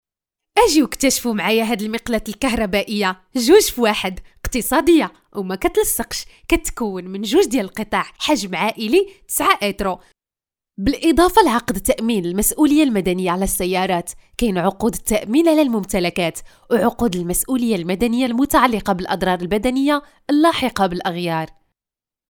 特点：轻快活力 大气浑厚 稳重磁性 激情力度 成熟厚重
阿拉伯语男女样音